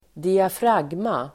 Ladda ner uttalet
Uttal: [²diafr'ag:ma]